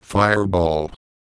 Worms speechbanks
Fireball.wav